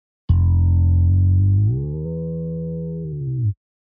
Бас-гитара со слайдами.